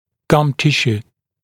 [gʌm ‘tɪʃuː] [-sjuː][гам ‘тишу:] [-сйу:]десневая ткань